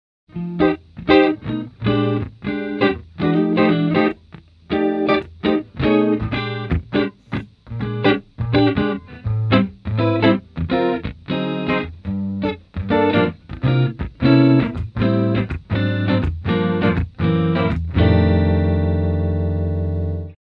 Traditional Chorus Ensenble
Compared with recent DSP (Digital Signal Processor), this specialty is Vintage feeling tone filter and long initial time, which make the warmer sound with space effect.
Demo with Humbucker Pickup